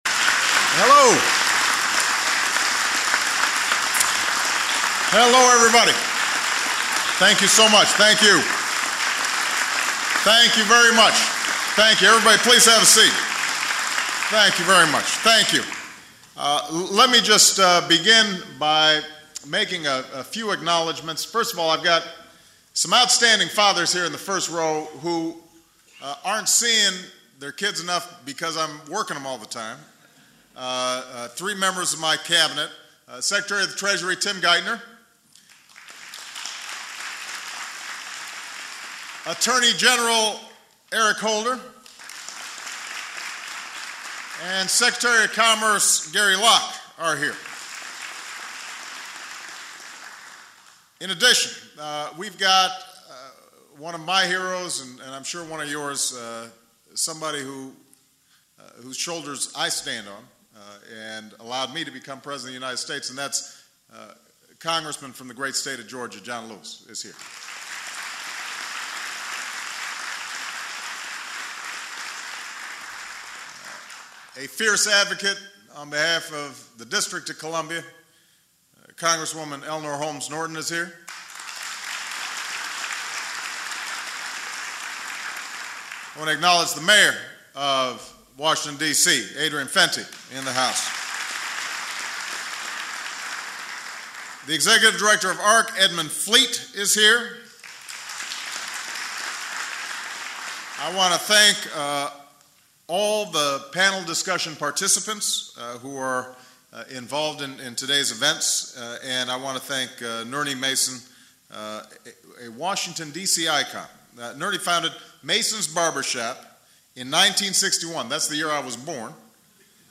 Barack Obama Father's Day 2010 Speech text audio video